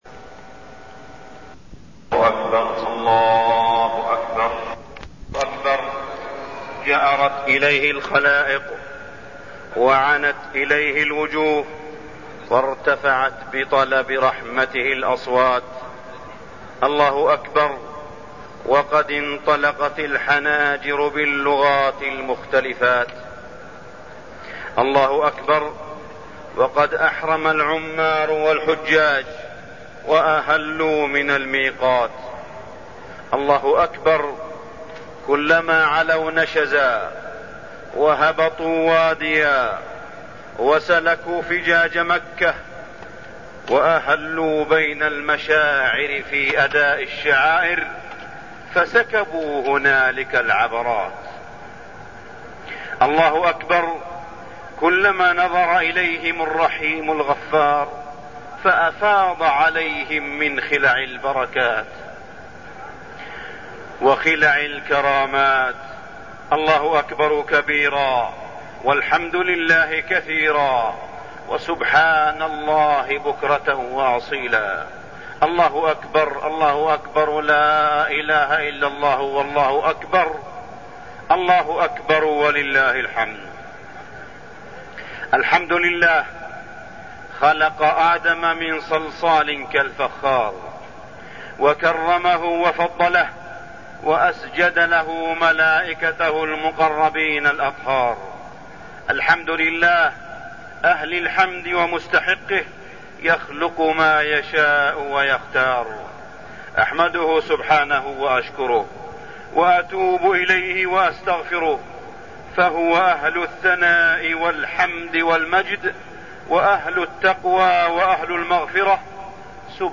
خطبة العيد-رعاية حجاج بيت الله
تاريخ النشر ١٠ ذو الحجة ١٤١٥ هـ المكان: المسجد الحرام الشيخ: معالي الشيخ أ.د. صالح بن عبدالله بن حميد معالي الشيخ أ.د. صالح بن عبدالله بن حميد خطبة العيد-رعاية حجاج بيت الله